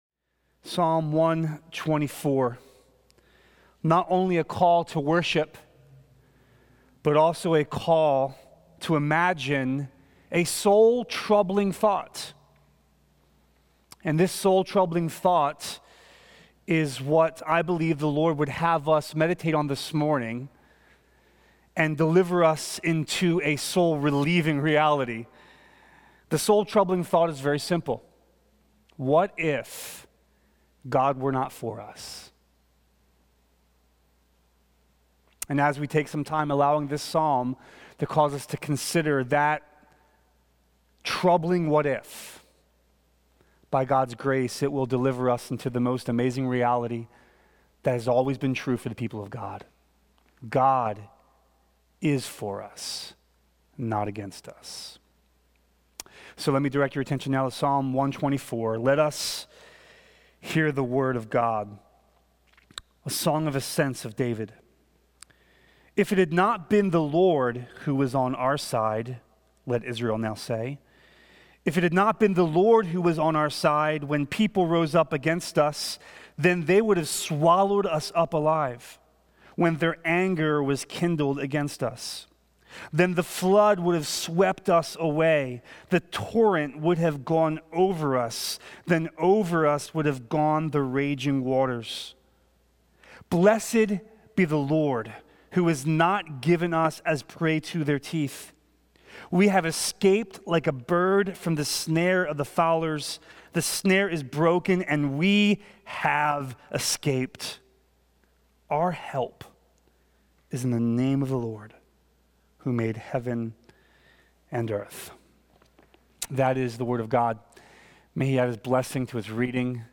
From Series: "Stand Alone Sermons"